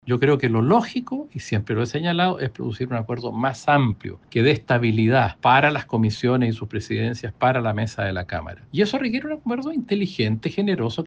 El diputado del partido, Jaime Mulet, dijo que lo ‘más lógico’ sigue siendo un acuerdo transversal que dé estabilidad a la cámara.